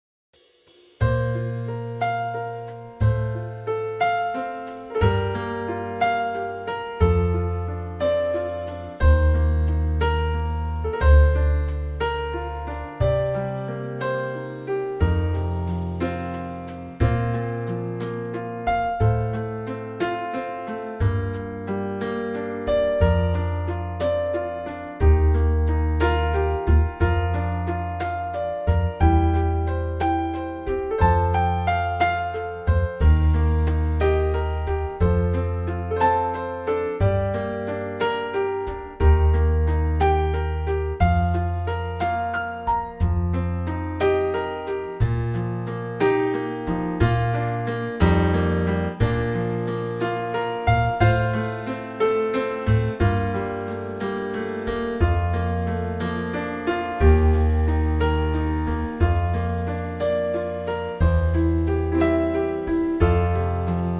Unique Backing Tracks
key - Bb - vocal range - F to A (optional Bb top note)
Gorgeous Trio arrangement